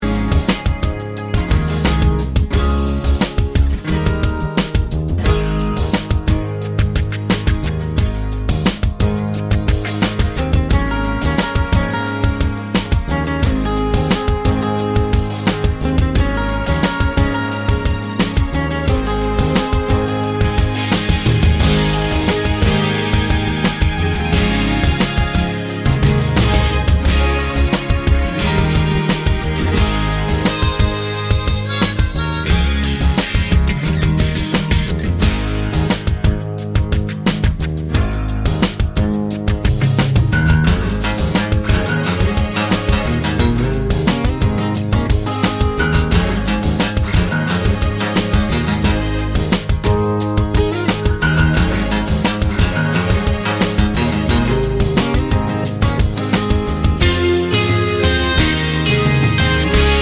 For Solo Singer Indie / Alternative 3:35 Buy £1.50